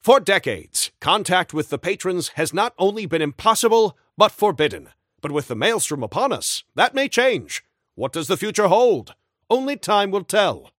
Newscaster_headline_79.mp3